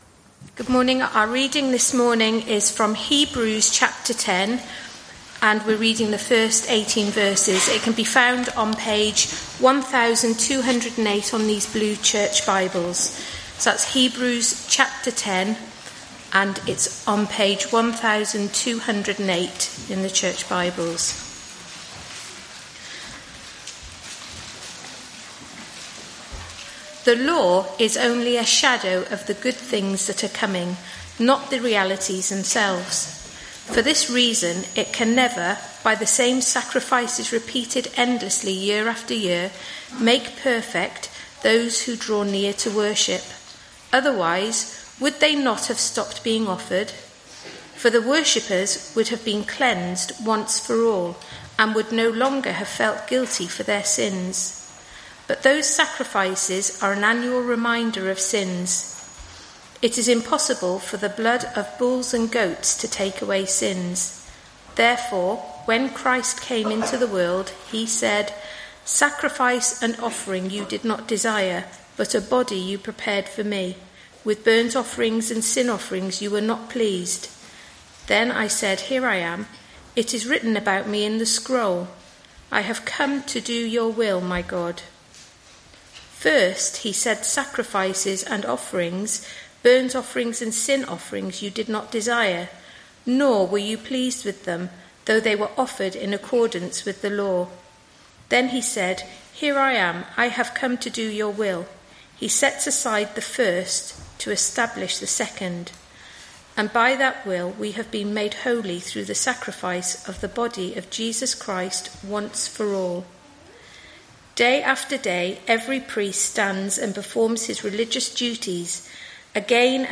Hebrews 10:1-18; 16 March 2025, Morning Service.